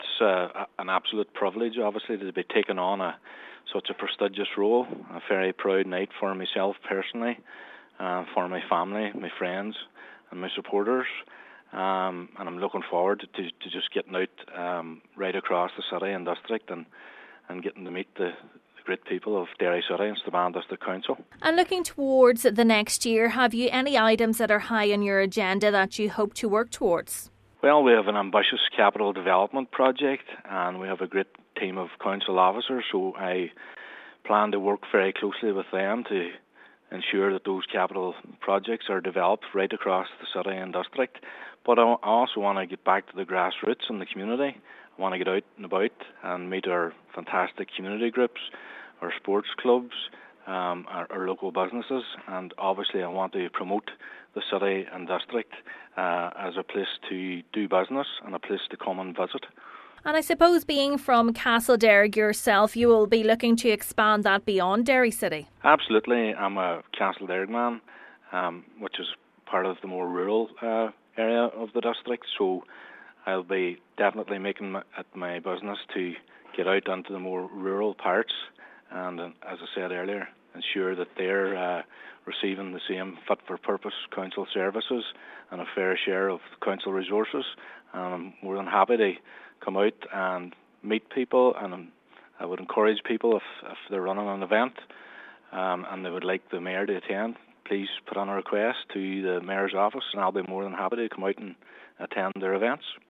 He says he will use his role to promote the area as a place to do business and visit: